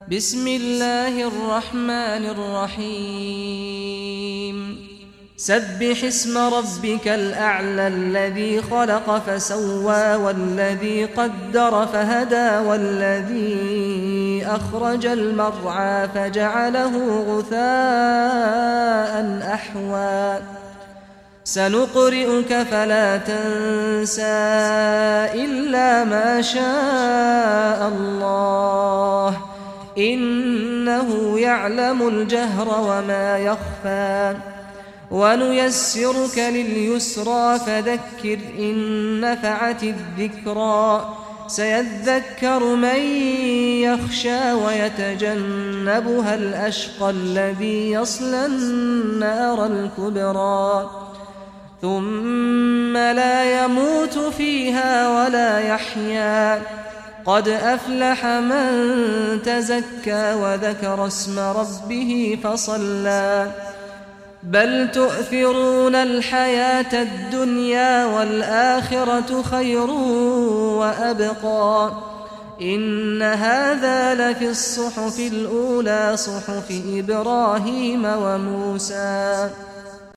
Surah Al Ala Recitation by Sheikh Saad al Ghamdi
Surah Ala, listen or play online mp3 tilawat / recitation in Arabic in the beautiful voice of Sheikh Saad al Ghamdi.